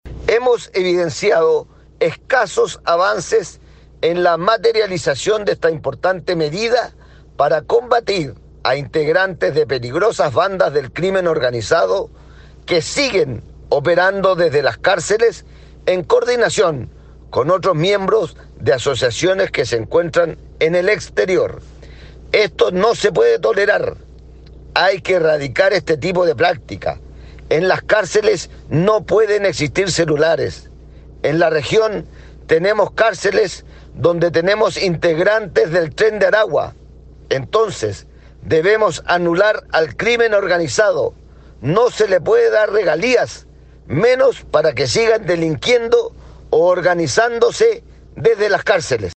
Al respecto el legislador señaló: